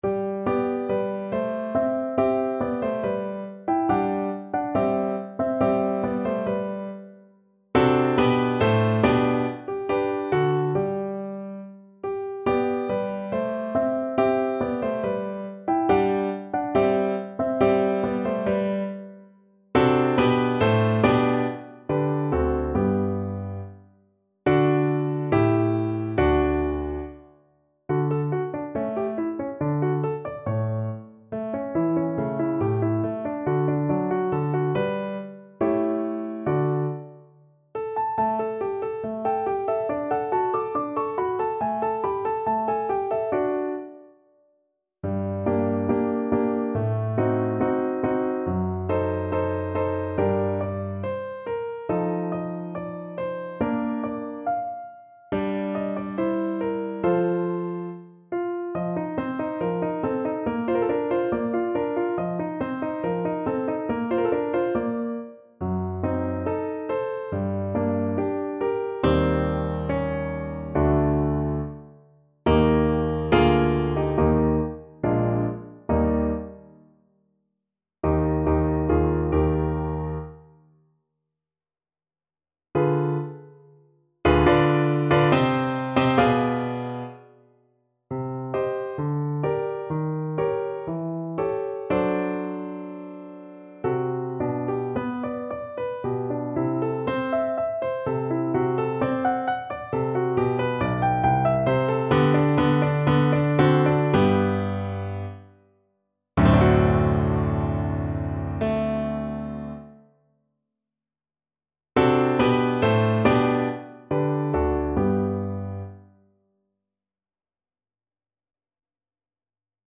Play (or use space bar on your keyboard) Pause Music Playalong - Piano Accompaniment Playalong Band Accompaniment not yet available reset tempo print settings full screen
= 70 Allegretto
G major (Sounding Pitch) (View more G major Music for Violin )
Classical (View more Classical Violin Music)